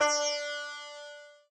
sitar_d.ogg